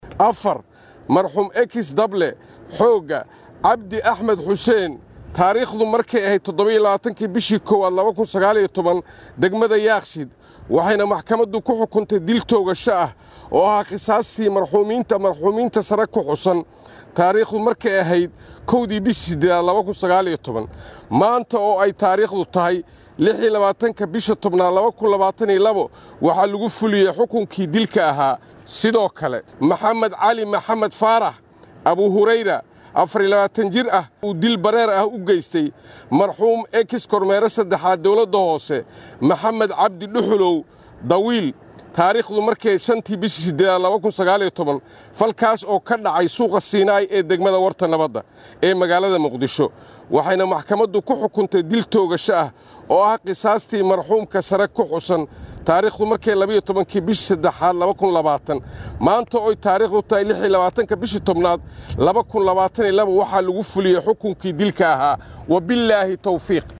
Janeraal Cabdullaahi Buule Kamey xeer ilaaliyaha guud ee ciidamada qalabka sida ee Soomaliya ayaa xukunka u akhriyay sidatan.